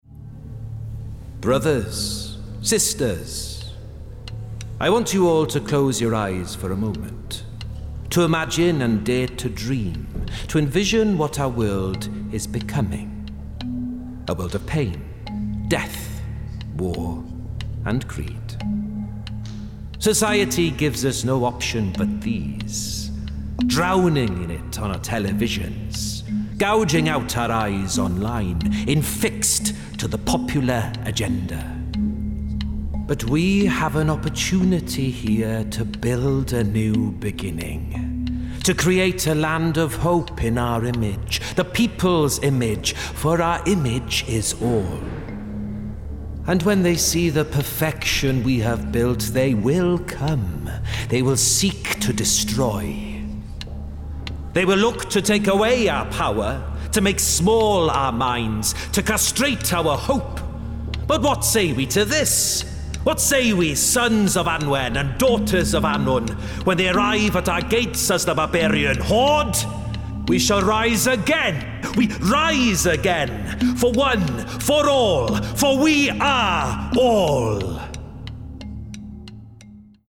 20/30's Welsh, Expressive/Warm/Natural
Cult Leader (Welsh)